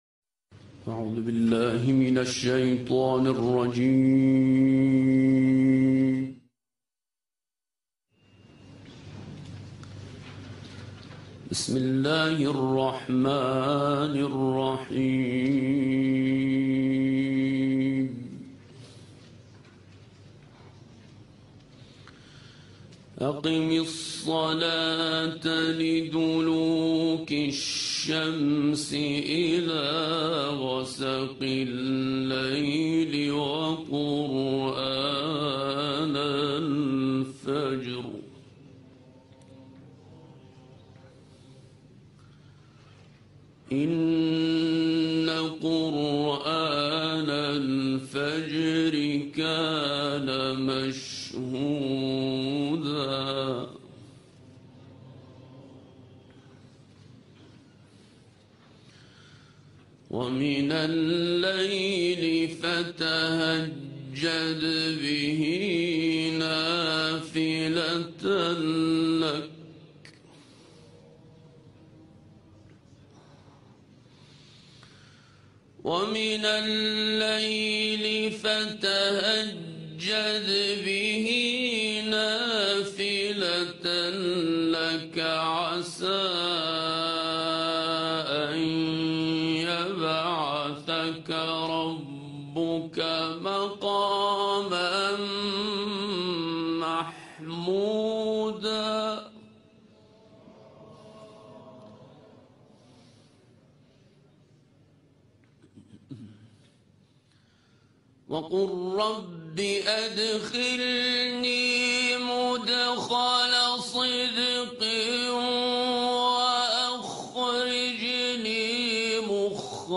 تلاوت در کانال‌های قرآنی/